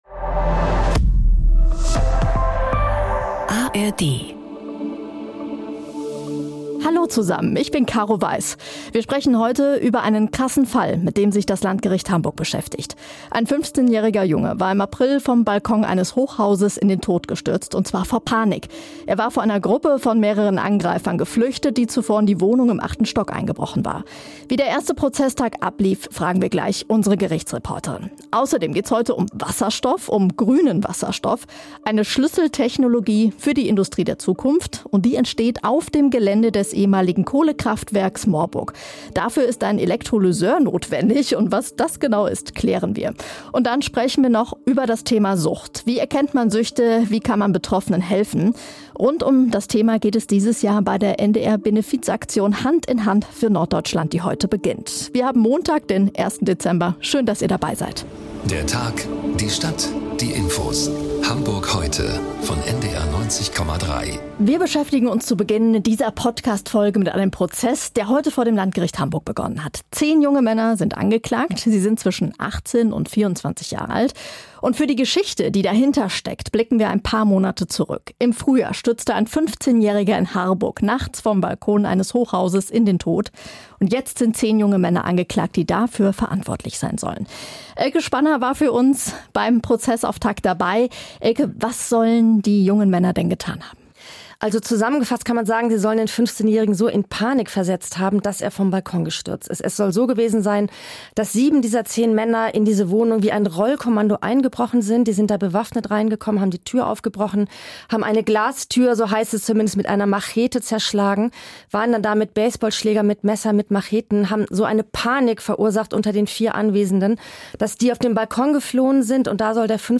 Tödlicher Balkonsturz in Harburg: zehn junge Männer vor Gericht ~ Hamburg Heute - Nachrichten Podcast